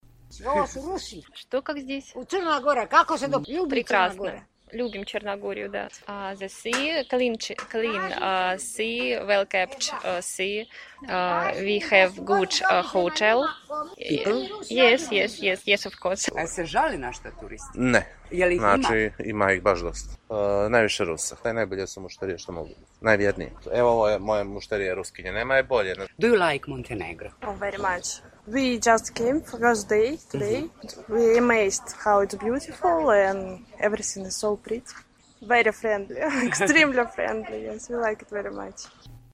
Prodavci na Risanskoj pijaci